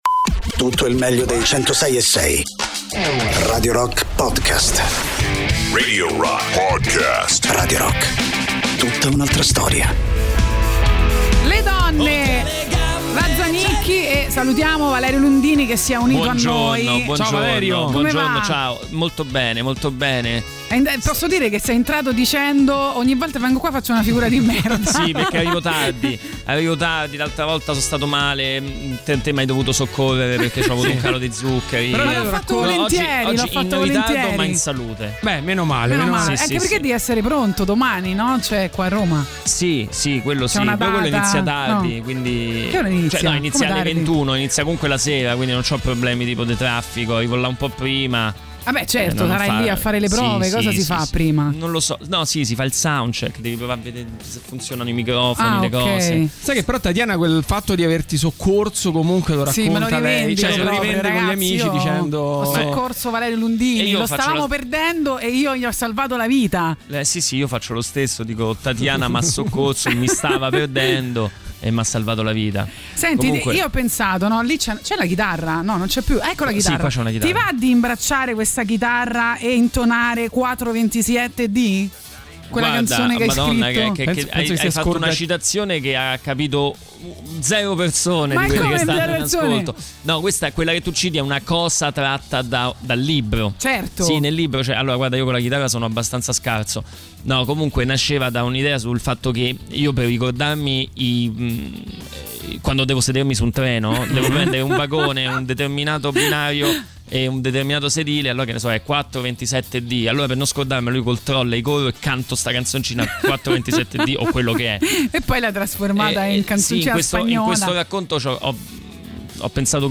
Interviste: Valerio Lundini (13-06-23)